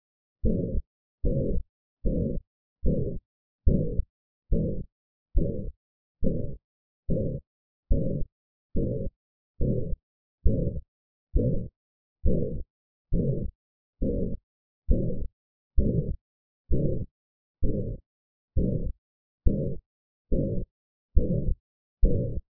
Ventricular Septal Defect (VSD)
Harsh holosystolic murmur
Left lower sternal border